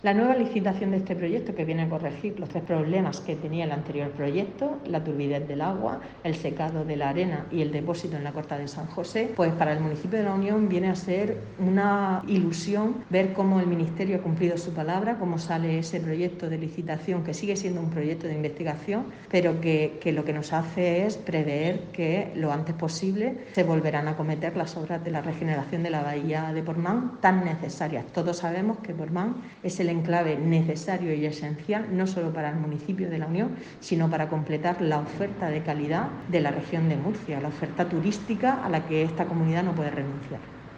Corte-Alcaldesa-en-funciones-de-La-Union-Elena-Lozano-sobre-proyecto-Portman.mp3